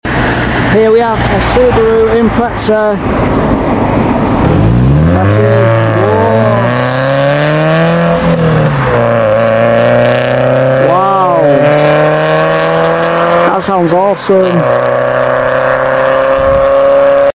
Subaru Impreza Accelaration At The sound effects free download